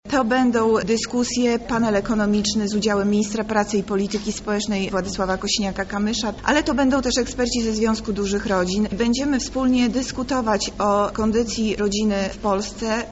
O założeniach oraz przygotowanych atrakcjach mówi Monika Lipińska – zastępca prezydenta Lublina.